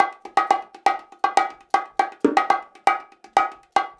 Index of /90_sSampleCDs/USB Soundscan vol.36 - Percussion Loops [AKAI] 1CD/Partition B/13-120BONGOS
120 BONGOS8.wav